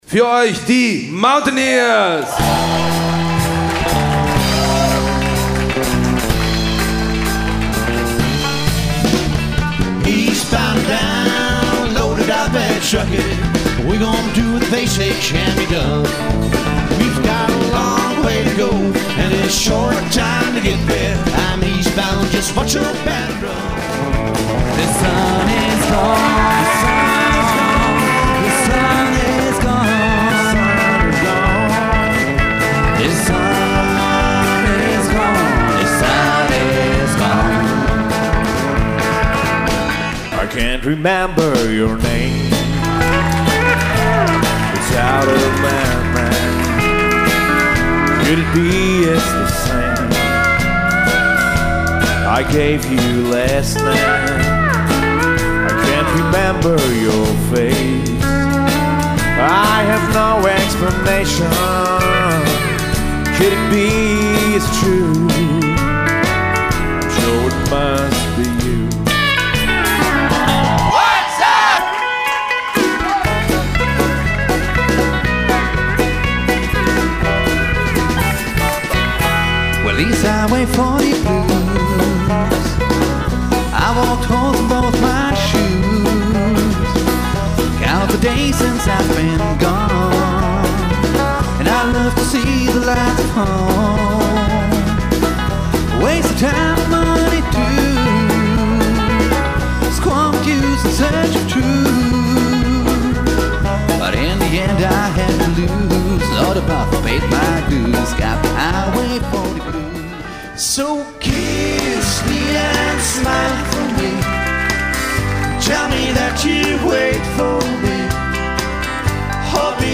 Einer Zeit, in der Country noch Country war.
Voc, Bass
Voc, Git, Banjo, Fiddle
Mandolin, Git
Pedal Steel Guitar, Dobro
Drums